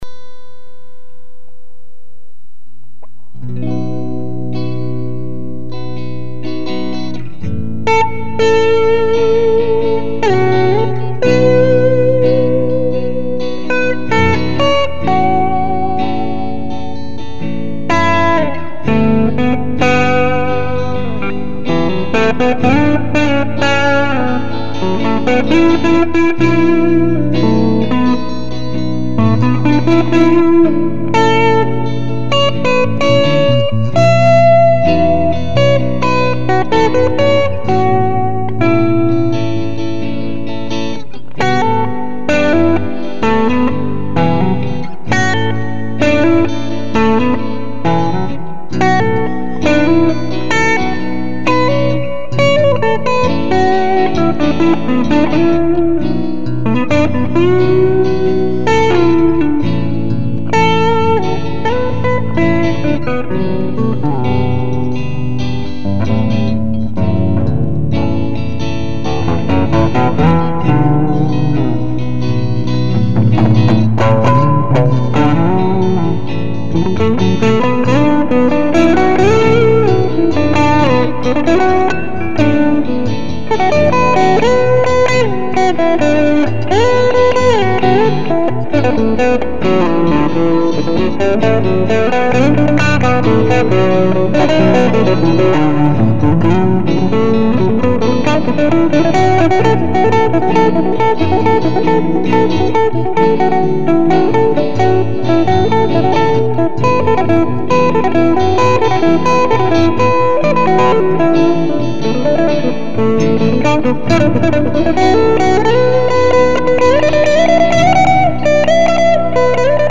This was a rather heart-felt recording at 2:00 a.m. one morning. Its got mistakes and all but I do like that its got a lot of feeling!